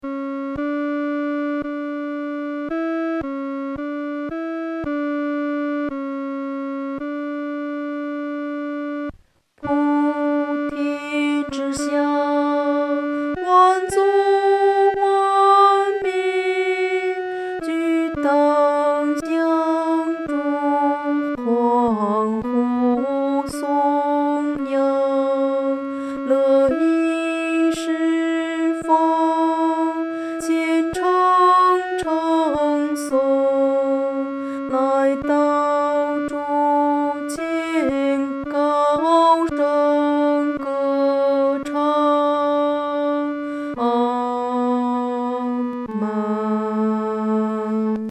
女低
本首圣诗由石家庄圣诗班录制